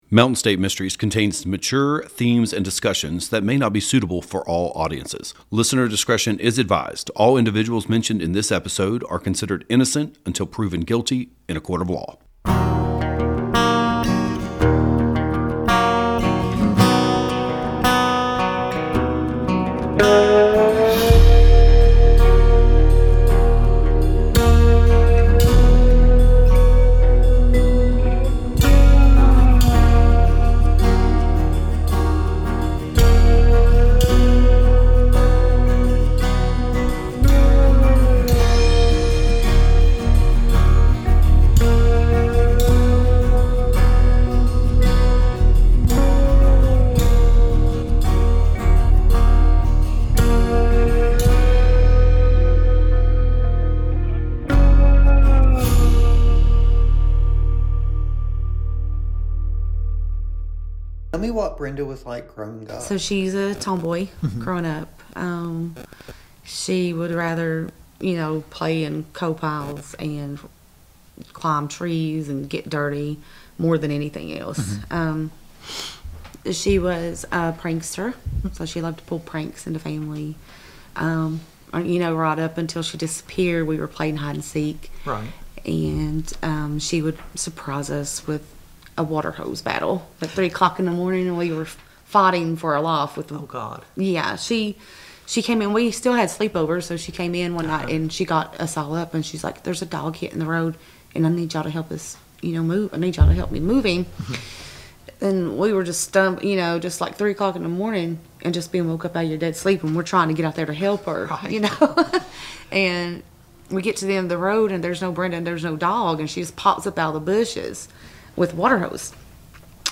From sharing heartfelt memories to discussing the unanswered leads in the case, this candid interview sheds light on the enduring pain of losing a loved one and the resilience of those left behind.